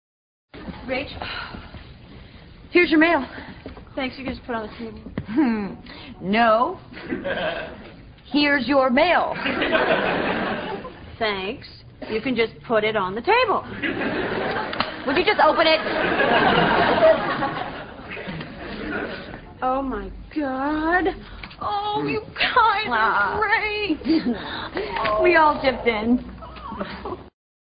可Monica不依不饶地硬是要把信给她，所以她一字一句地说：No, here's your mail.通常以这样的语气来说话就表示一种强调，这封信肯定有什么重要之处。